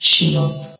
S.P.L.U.R.T-Station-13 / sound / vox_fem / shield.ogg
CitadelStationBot df15bbe0f0 [MIRROR] New & Fixed AI VOX Sound Files ( #6003 ) ...
shield.ogg